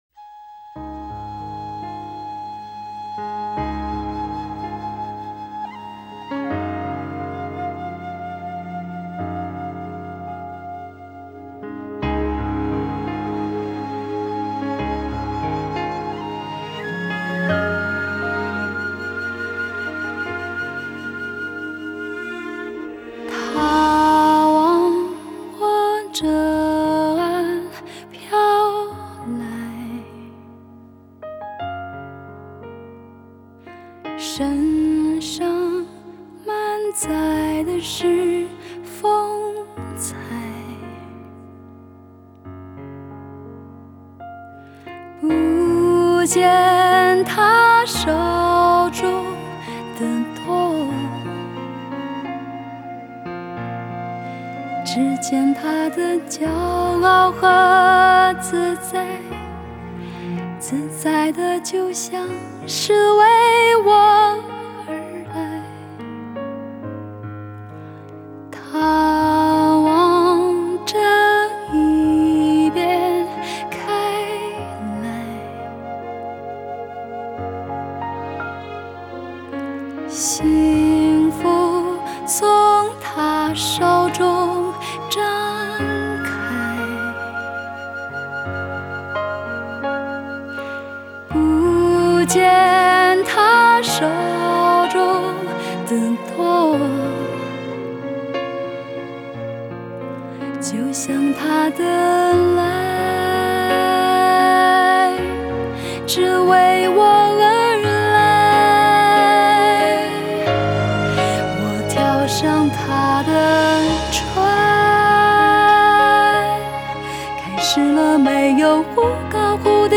Ps：在线试听为压缩音质节选，体验无损音质请下载完整版 作词